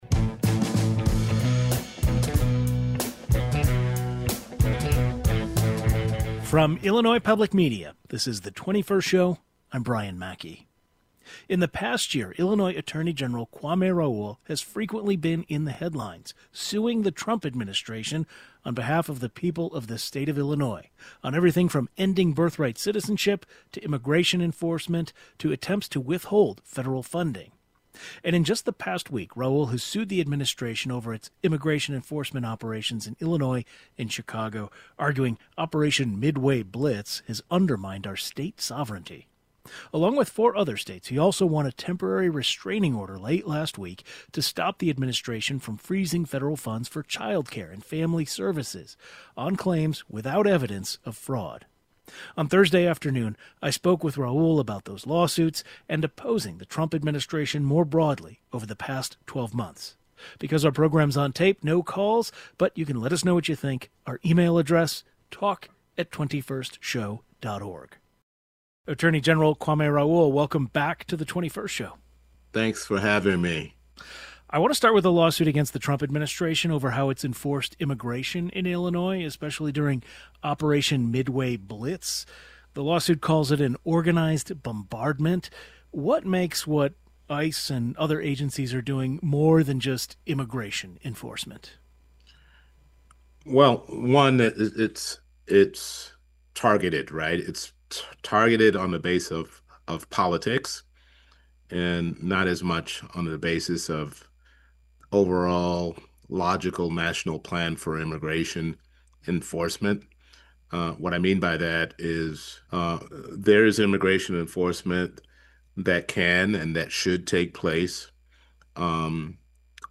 Illinois has been fighting the Trump administration in court over ICE activity, National Guard deployments, and government funding. We’ll talk about that with the state’s top legal officer, Illinois Attorney General Kwame Raoul.
Kwame RaoulAttorney General of Illinois (D)